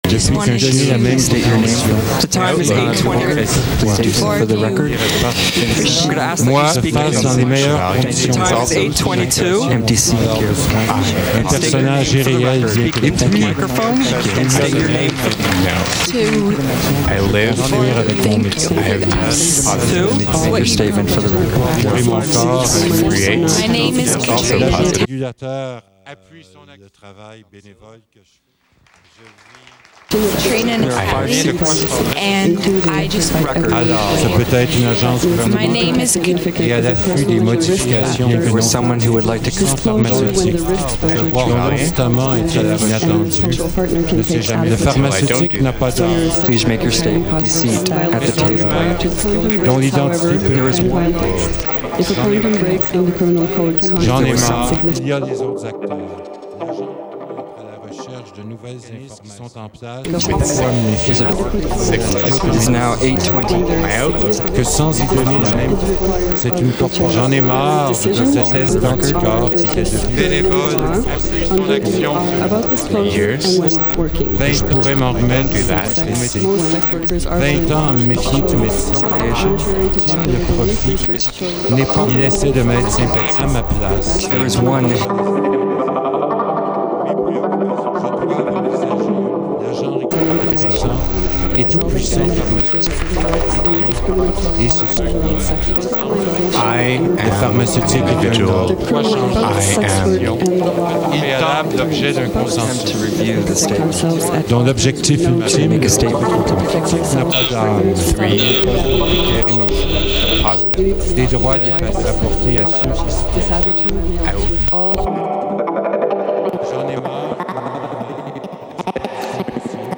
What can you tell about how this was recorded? Concordia University, Tuesday, 4 April 2006